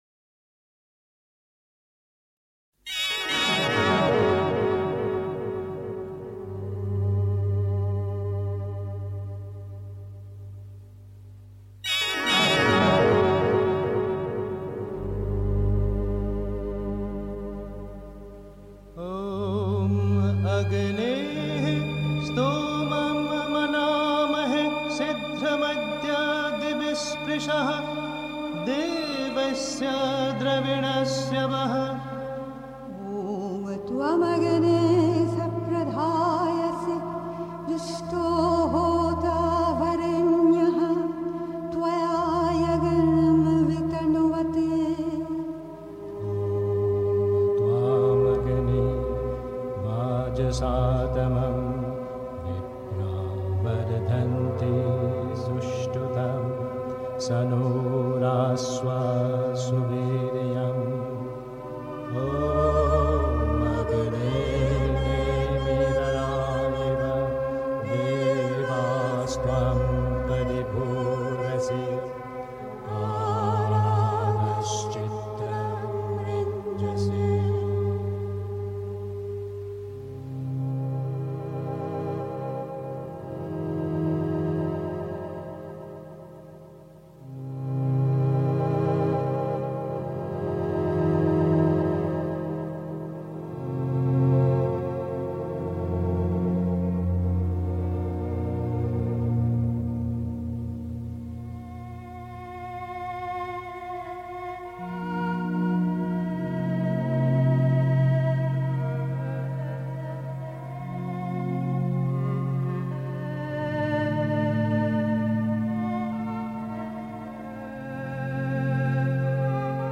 Beschreibung vor 1 Jahr 1. Einstimmung mit Sunils Musik aus dem Sri Aurobindo Ashram, Pondicherry. 2. Verliere niemals die Hoffnung (Die Mutter, Weisse Rosen, 21. März 1958) 3. Zwölf Minuten Stille.